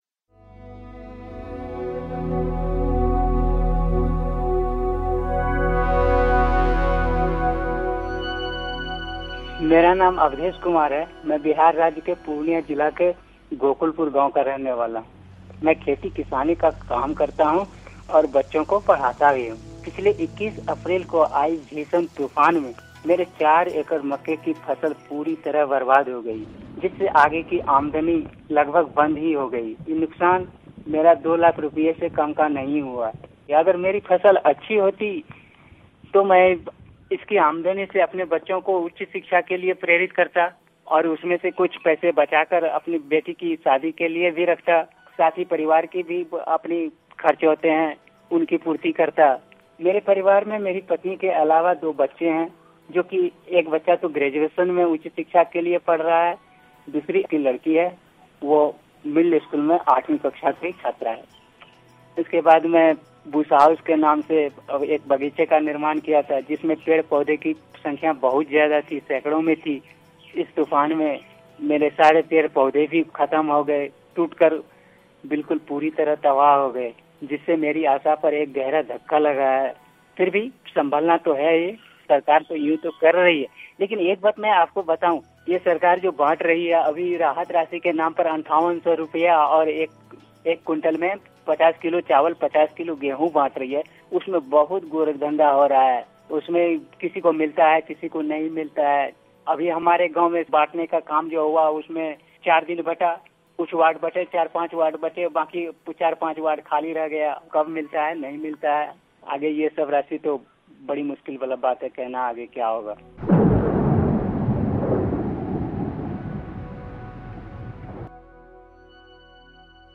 उन्हीं की ज़ुबानी.